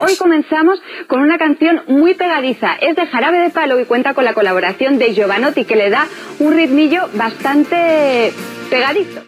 Presentació d'un tema musical
Musical